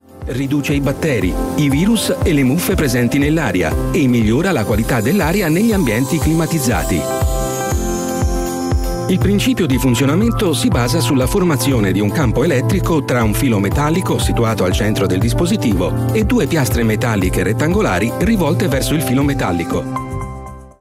Profonde, Chaude, Corporative, Commerciale, Senior, Mature
Commercial